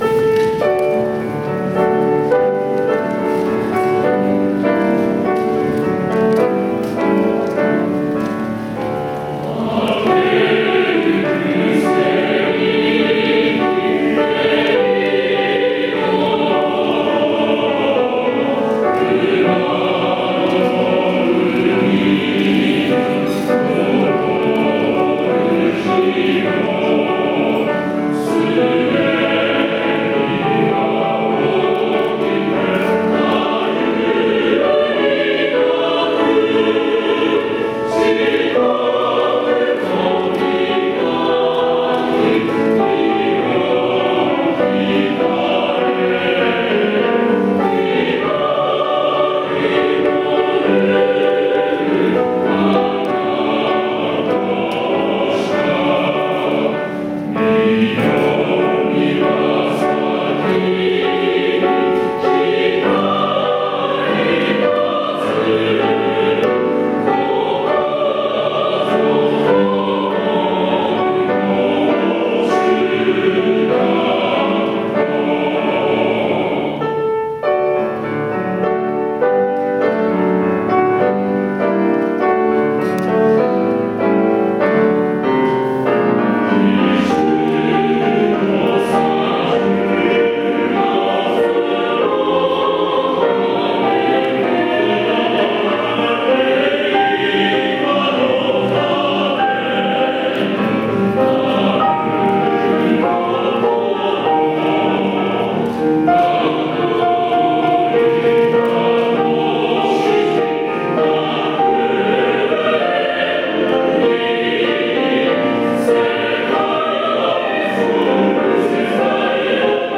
校歌
光洋中学校校歌_新国立劇場合唱団.mp3